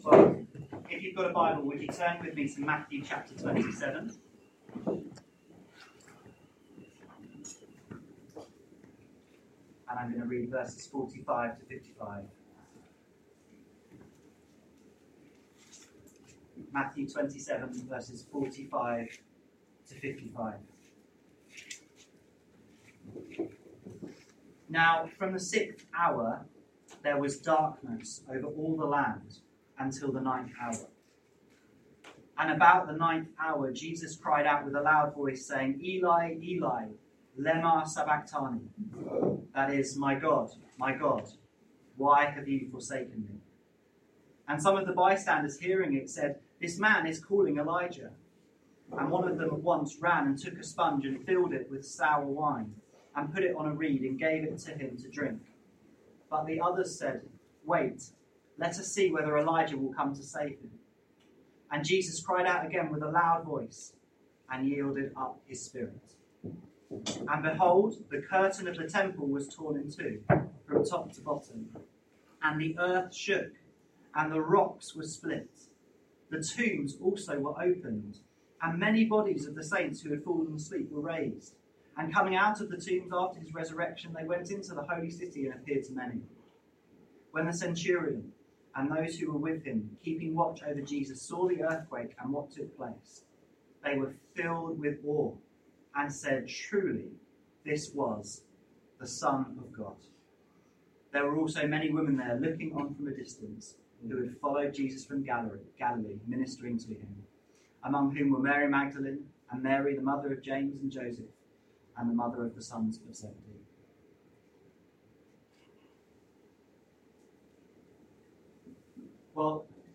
This sermon addresses the mixed emotions of Good Friday by unpacking these precious verses.